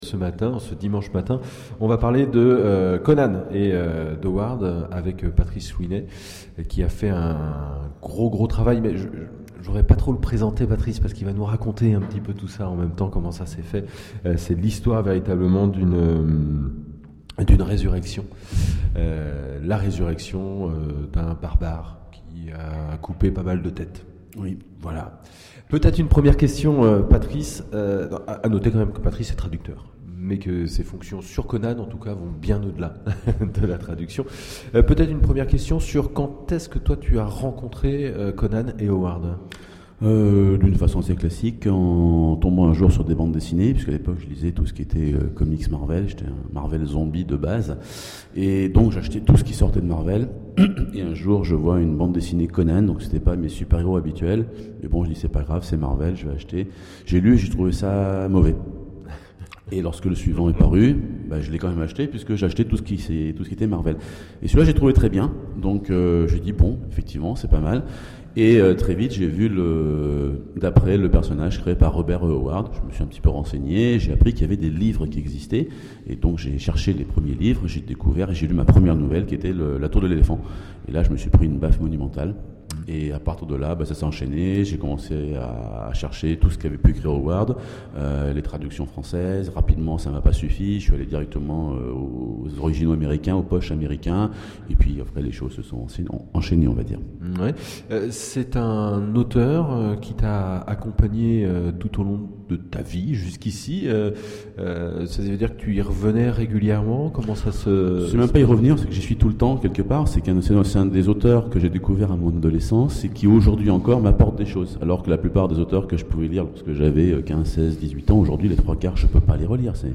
Imaginales 2013 : Conférence Conan, l'intégrale